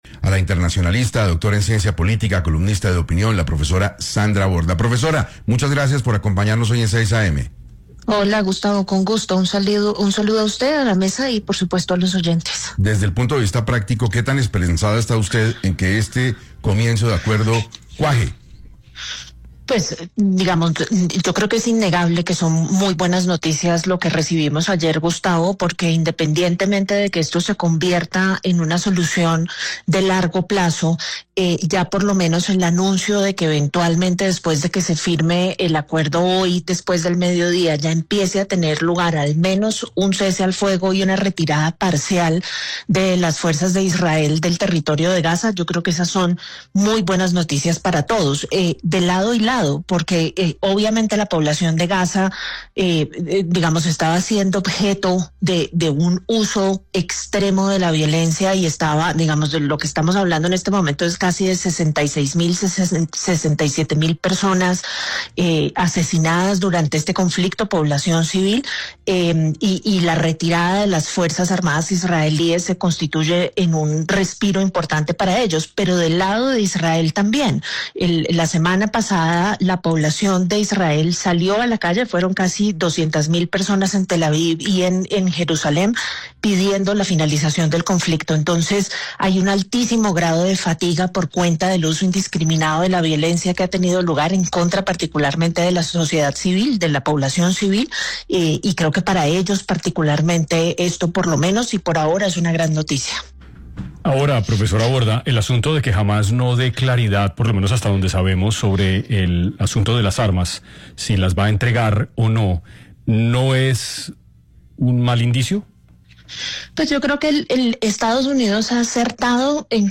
En diálogo con 6AM de Caracol Radio